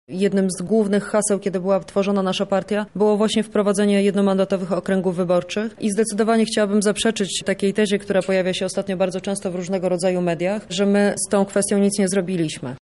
– mówi Joanna Mucha z lubelskiej PO.